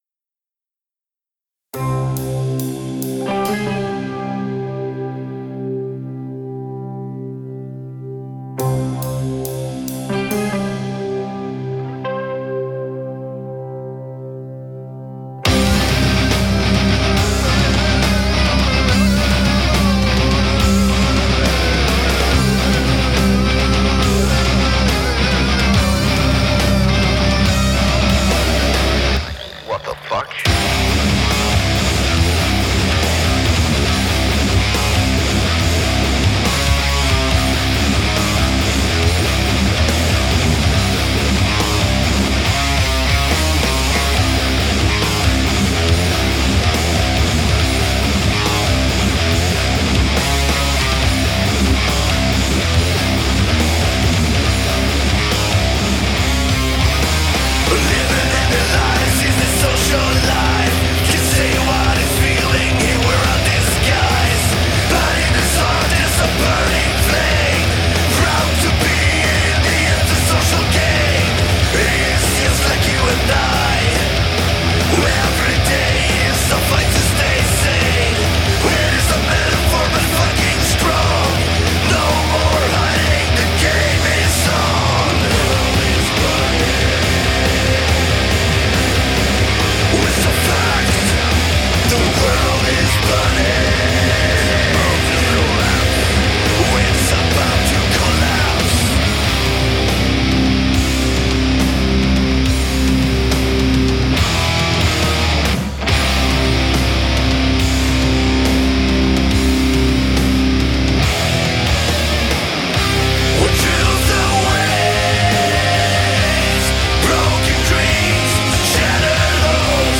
die dem melodischen Metalcore verfallen sind